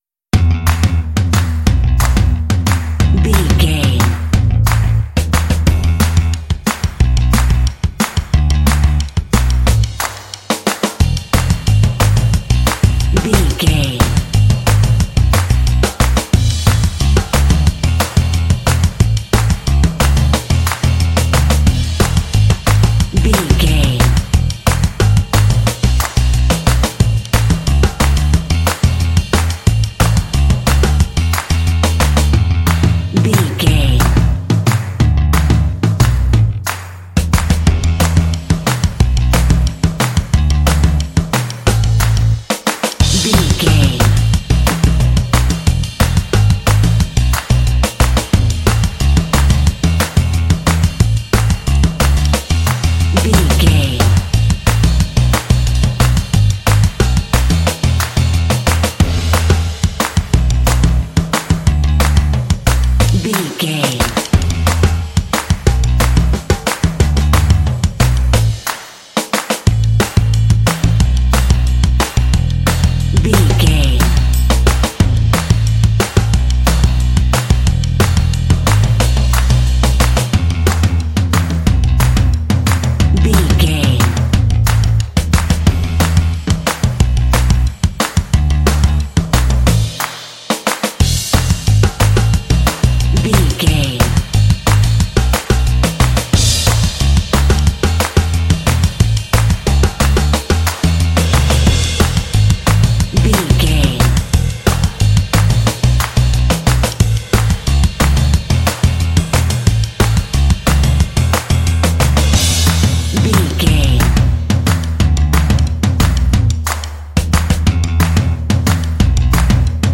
Ionian/Major
Fast
cheerful/happy
lively
energetic
playful
drums
bass guitar
alternative rock